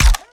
GUNAuto_RPU1 B Fire_05_SFRMS_SCIWPNS.wav